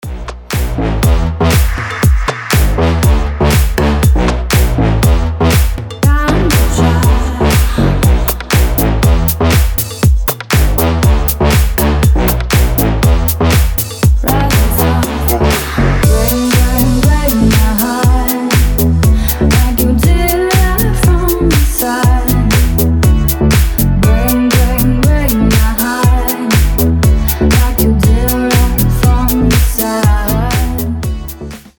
женский вокал
deep house
басы
Стиль: deep house